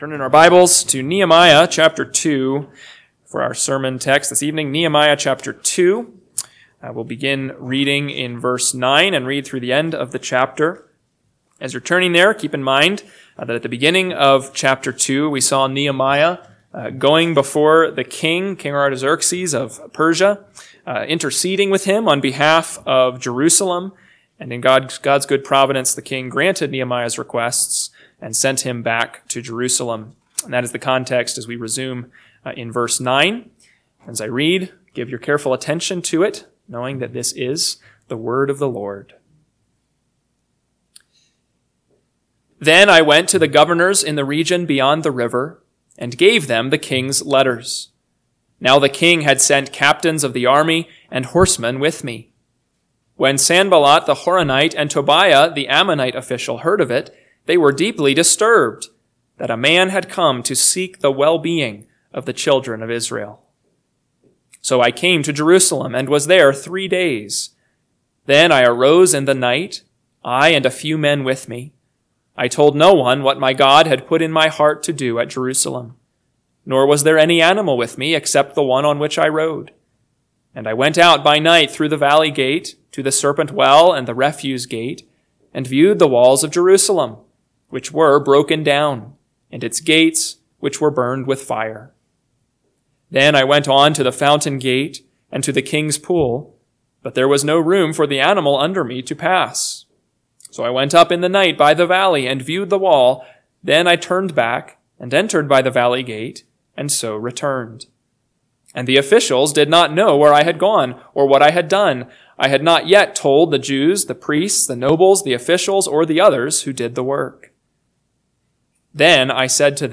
PM Sermon – 6/29/2025 – Nehemiah 2:9-20 – Northwoods Sermons